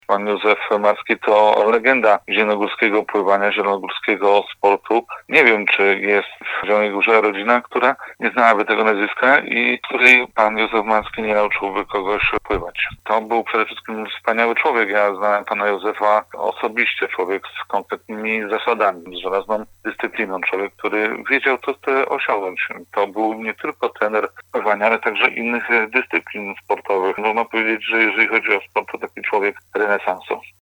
Wspominając zmarłego, radny Jacek Budziński podkreśla